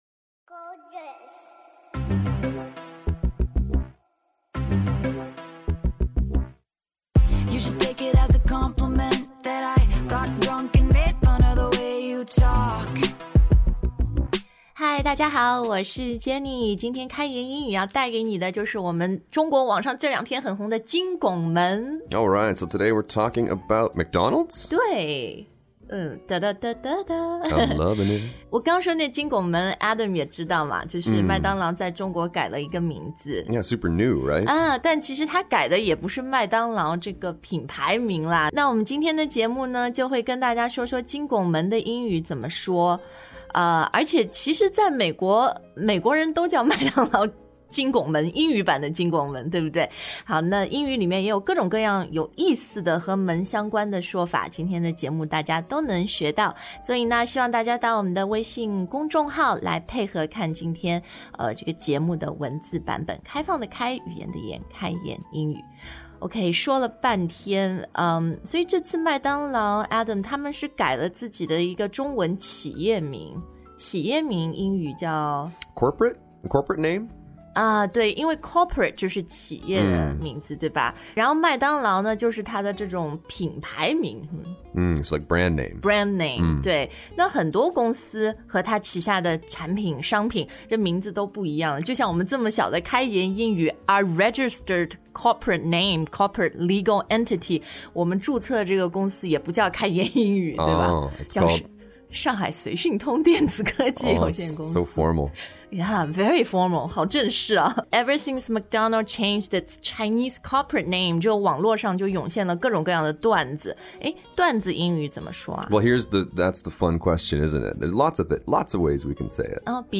Arch: 拱形，读音[ɑrtʃ]
Arc de Triomphe （法语）: 凯旋门, 发音是/ark də triˈɔ̃f/